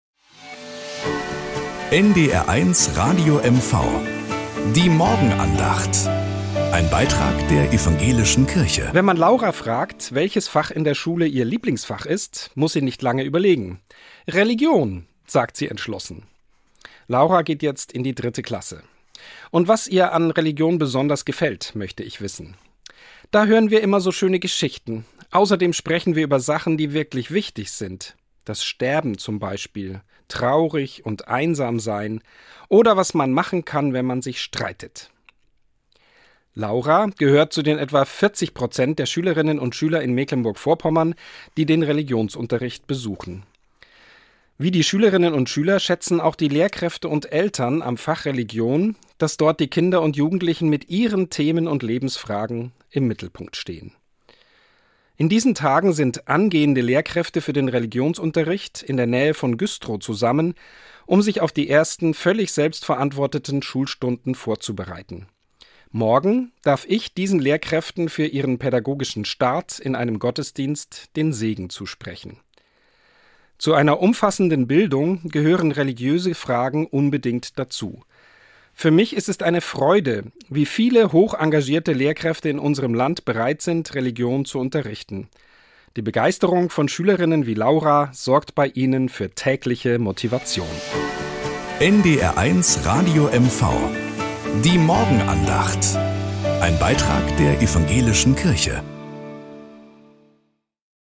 Nachrichten aus Mecklenburg-Vorpommern - 27.05.2025